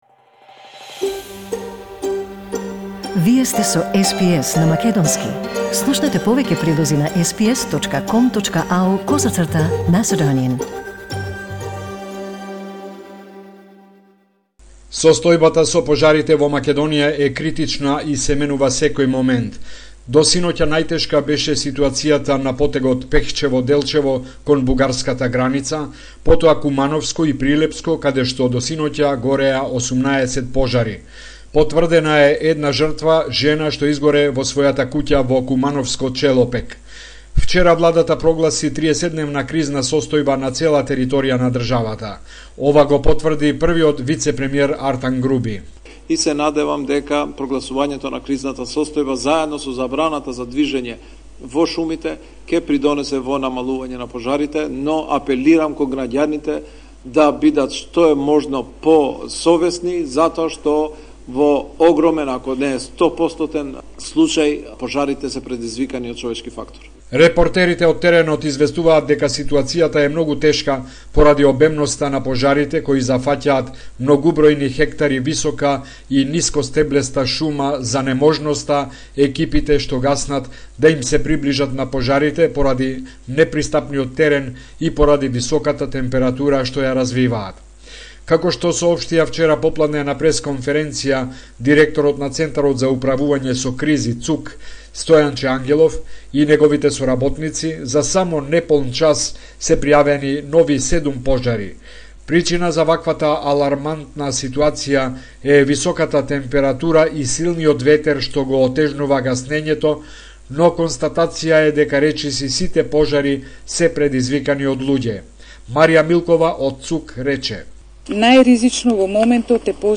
Homeland Report in Macedonian 6 August 2021